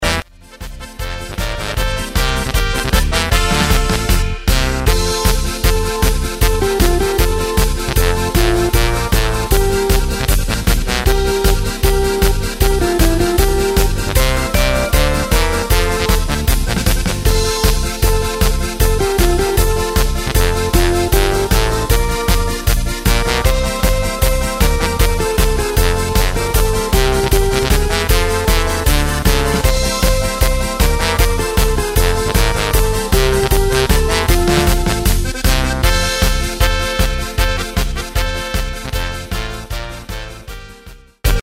Takt:          2/4
Tempo:         155.00
Tonart:            F
Flotte Polka aus dem Jahr 2011!